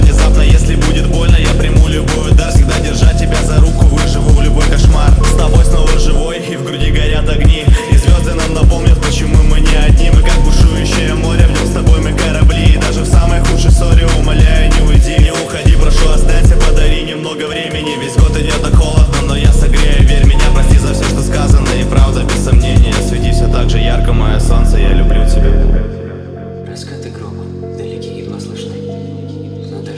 Жанр: Танцевальные / Русские